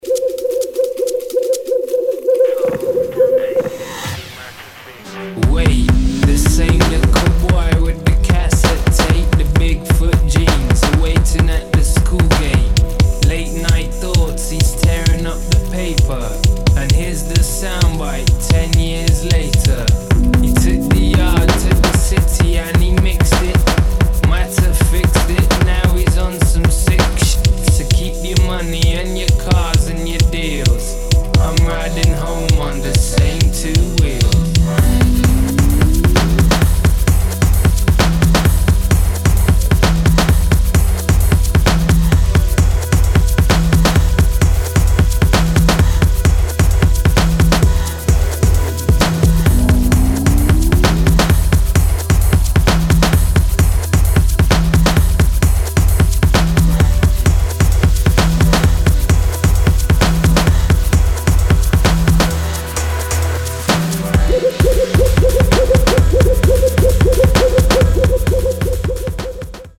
[ BASS ]